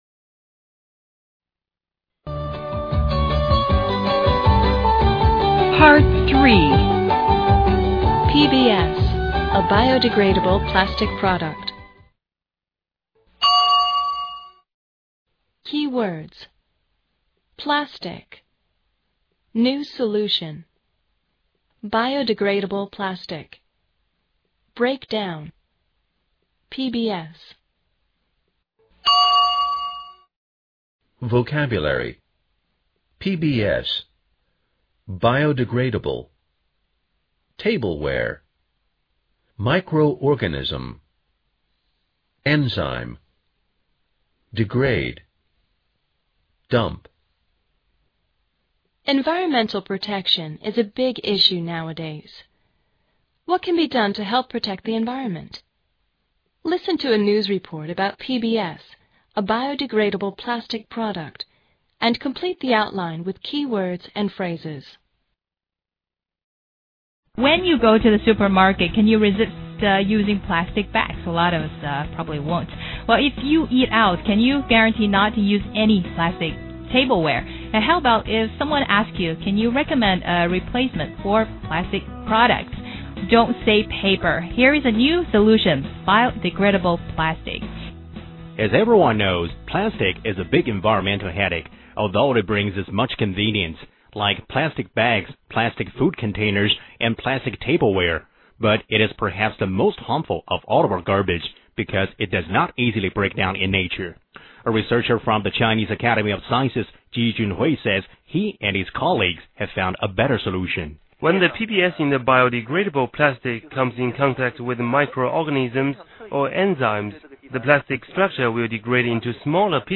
Listen to a news report about PBS—— a biodegradable plastic product, and complete the outline with keywords and phrases.